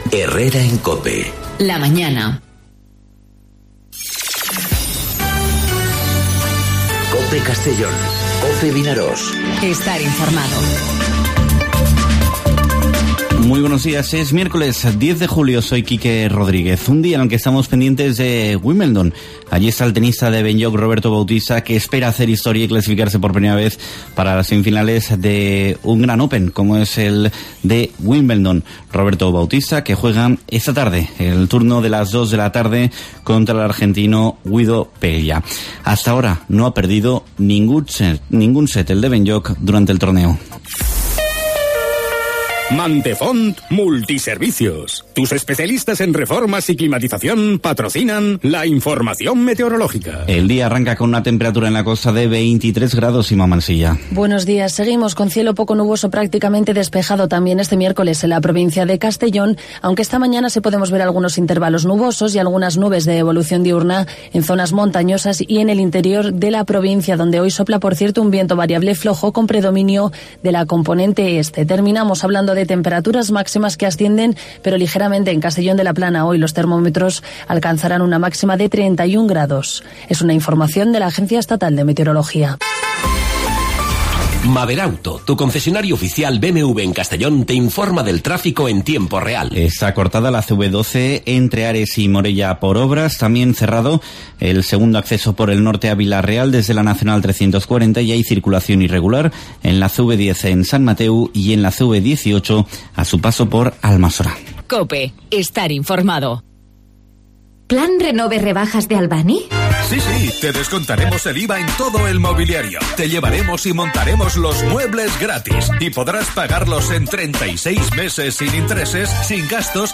Informativo 'Herrera en COPE' Castellón (10/07/2019)